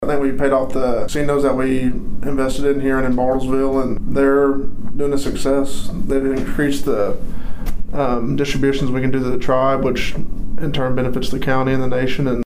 Pawhuska's Chamber of Commerce hosted a lunch and learn series at the Osage Casino and Hotel in Pawhuska on Wednesday.